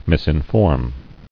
[mis·in·form]